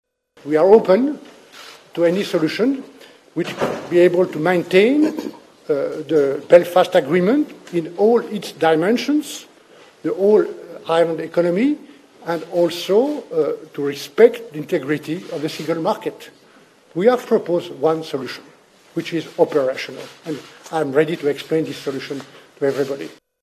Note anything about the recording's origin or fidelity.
Speaking in Derry he said that there may be other solutions, but the backstop is one that’s workable and that’s been agreed to: